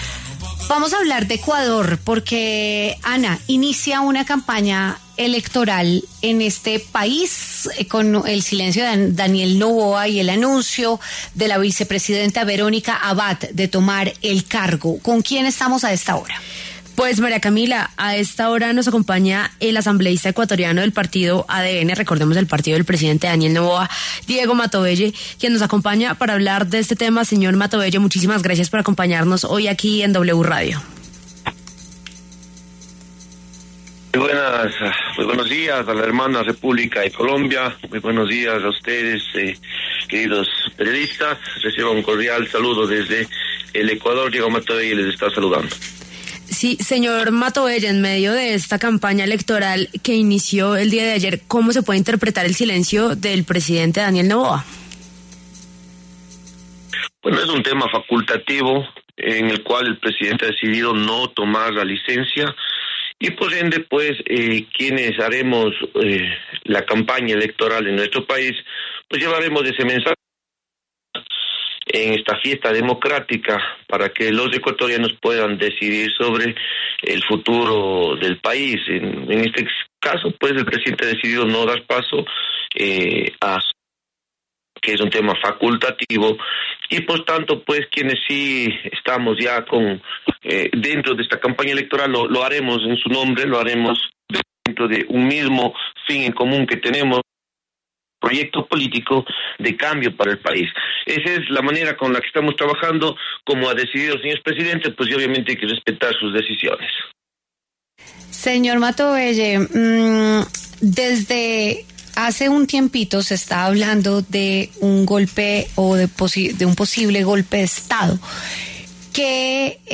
Diego Matovelle, asambleísta ecuatoriano del partido ADN, explicó a qué se debe el silencio de Noboa.